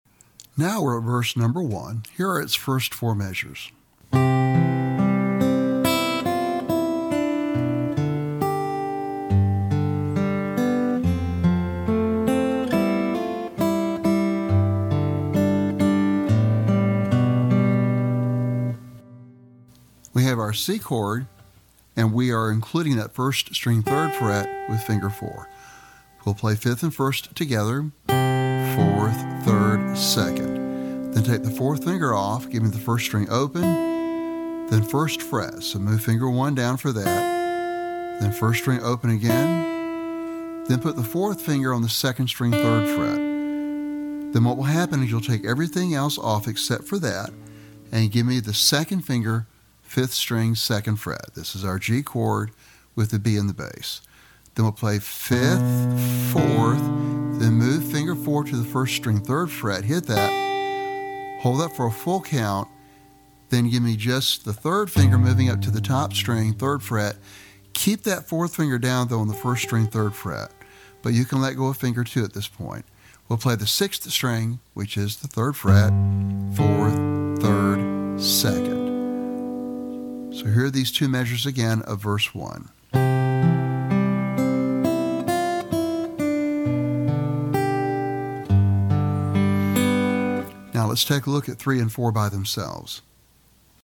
Fingerstyle Guitar Solo  Sample
Lesson Sample